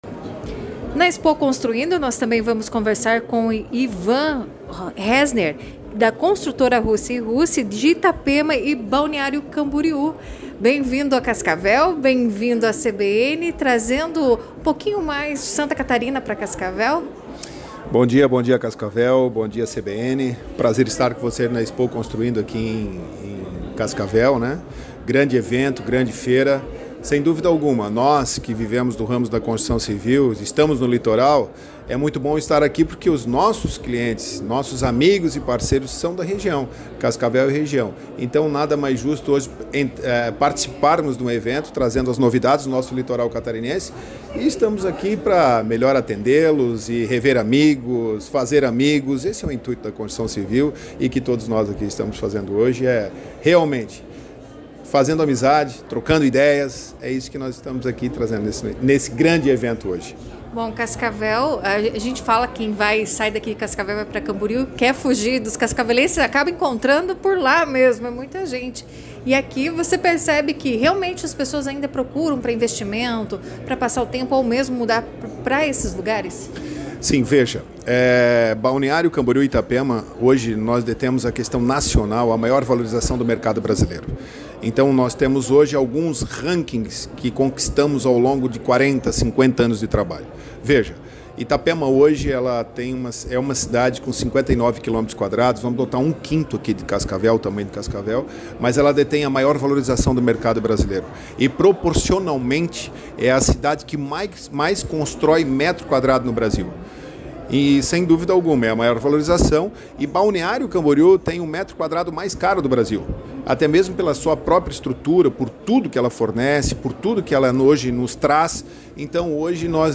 em entrevista à CBN Cascavel falou sobre investimentos em Balneário Camboriú e em Itapema.